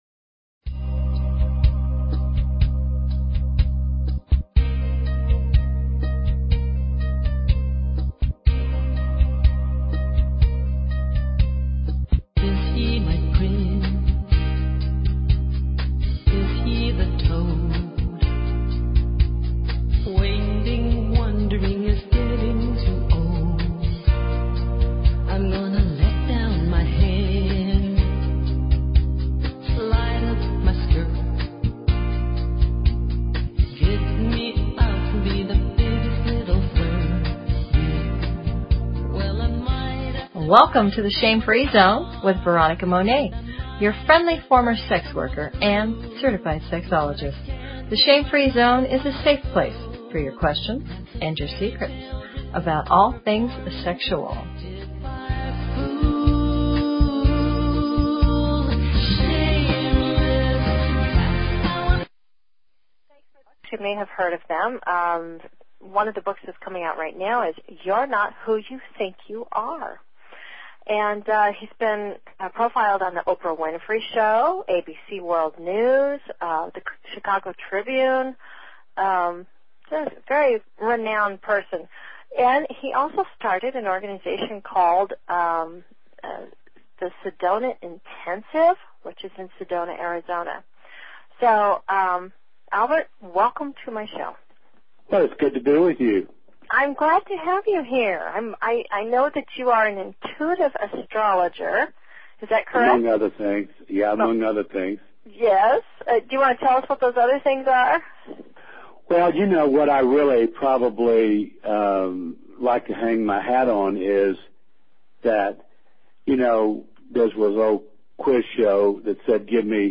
Talk Show Episode, Audio Podcast, The_Shame_Free_Zone and Courtesy of BBS Radio on , show guests , about , categorized as